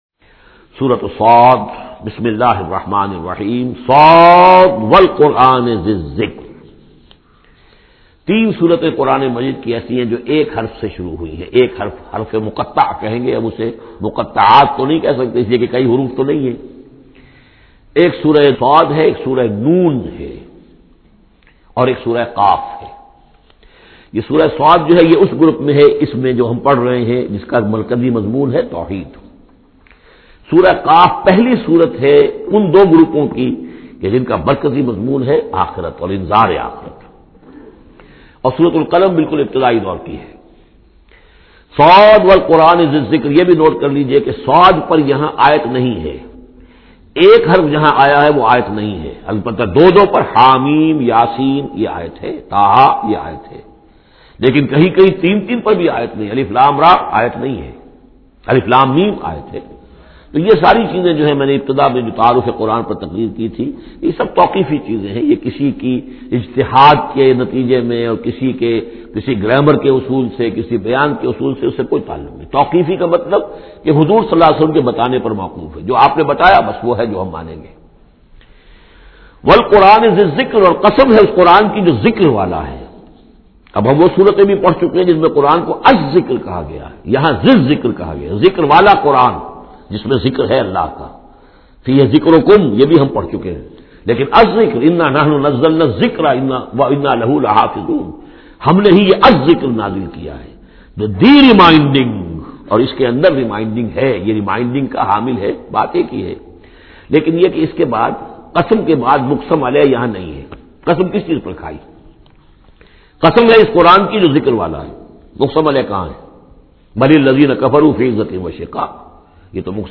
Listen online and download beautiful Quran Tafseer of Surah Sad in the voice of Dr Israr Ahmed.